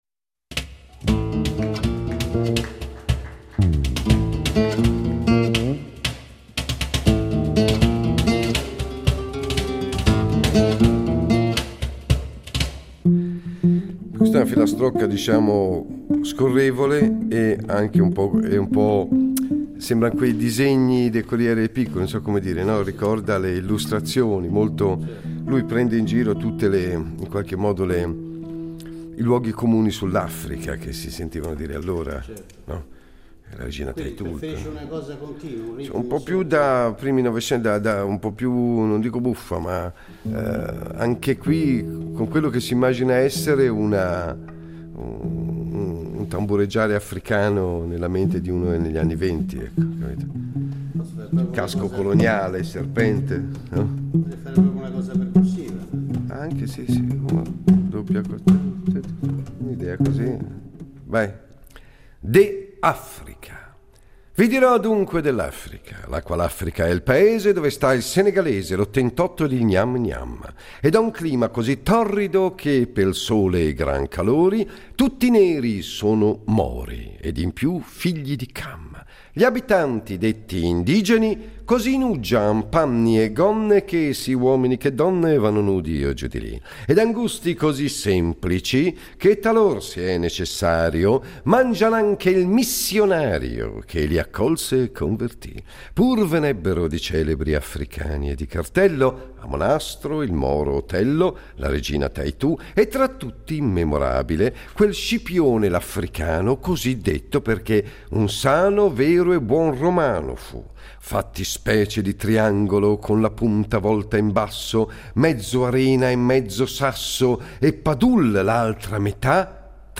Attraverso le registrazioni dal vivo delle spericolate performaces poetico-musicale dell'autore, cantautore, attore e regista David Riondino, Colpo di poesia torna a omaggiare una delle voci più singolari della letteratura italiana a cavallo fra Ottocento e Novecento: il poeta piemontese Ernesto Ragazzoni (Orta San Giulio, 1870 – Torino, 1920).